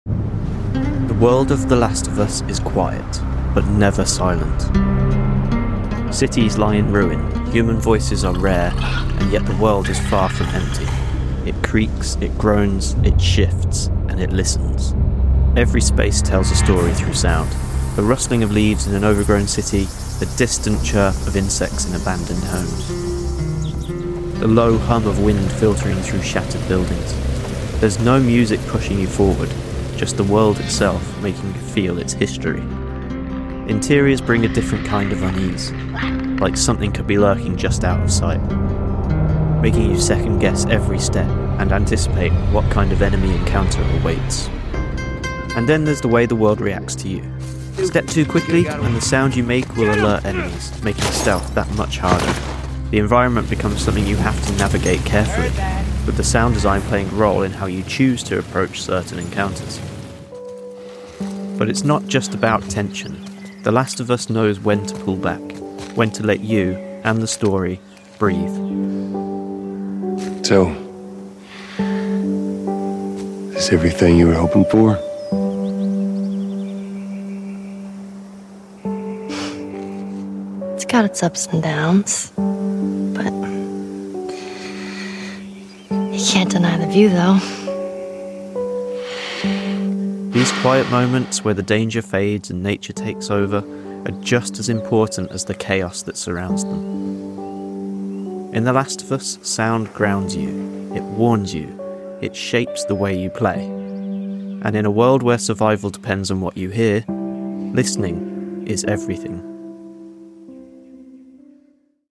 It creaks, it groans, it shifts - it listens.
The rustling of leaves in an overgrown city, the distant chirp of insects in abandoned homes, the low hum of wind filtering through shattered buildings. There’s no music pushing you forward - just the world itself, making you feel its history.